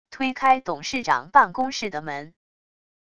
推开董事长办公室的门wav音频